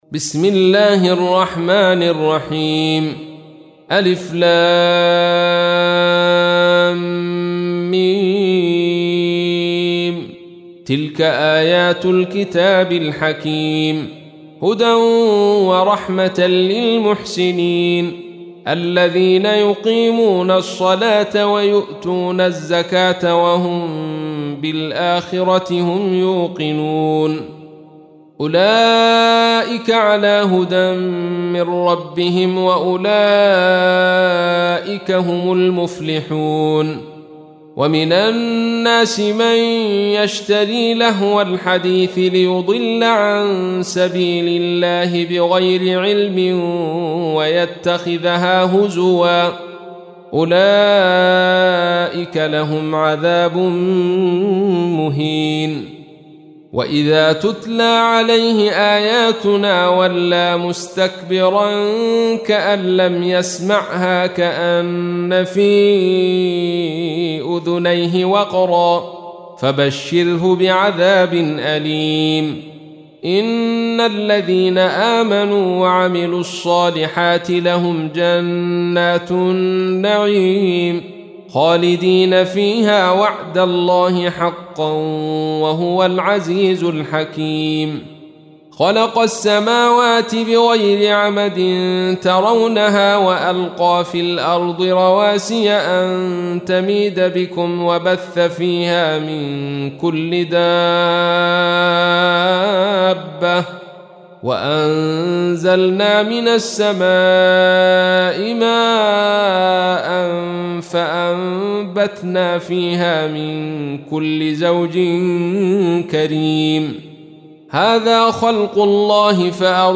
تحميل : 31. سورة لقمان / القارئ عبد الرشيد صوفي / القرآن الكريم / موقع يا حسين